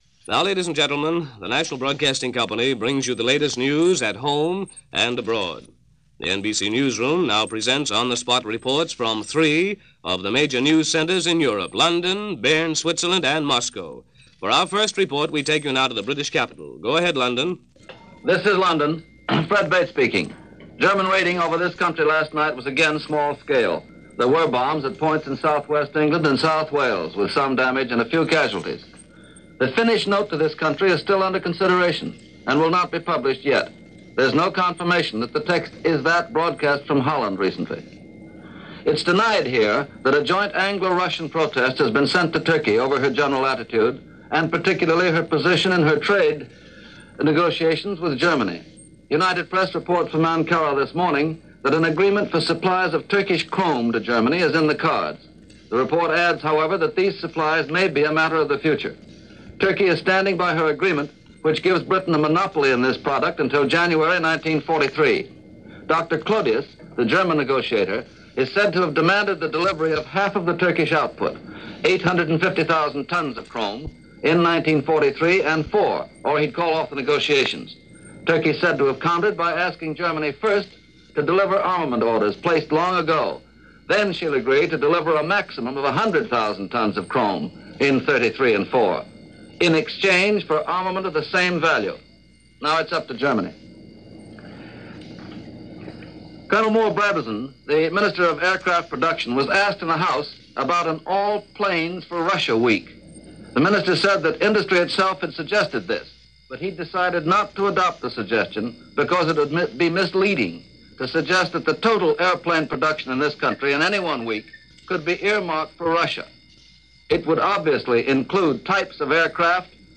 October 9, 1941 - The Eastern Front, The Trouble With Turkey And No Exemptions For The Oxford Group -news for this day in 1941 as reported by NBC Radio.